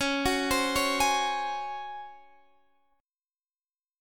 C#M7sus4 chord